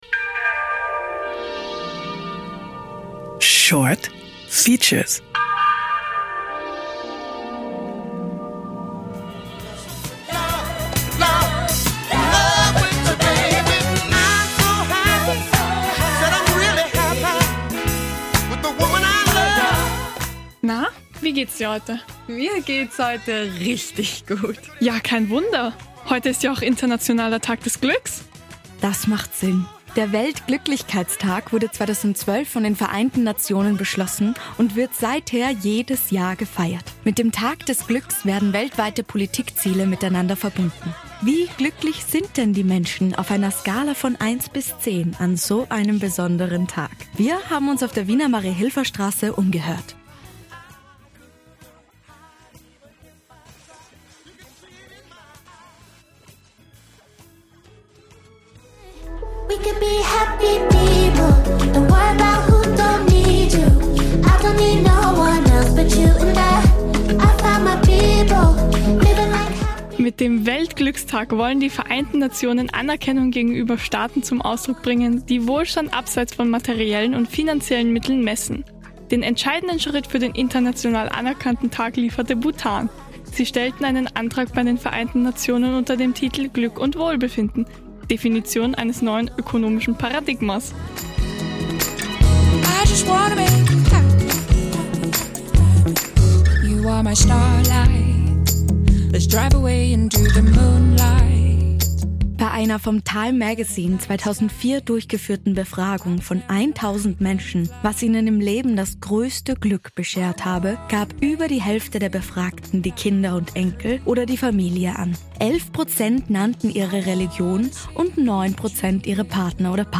Wie glücklich sind denn die Menschen auf einer Skala von 1 bis 10 an so einem besonderen Tag? Wir haben uns auf der Wiener Mariahilferstraße umgehört und spannende Antworten bekommen!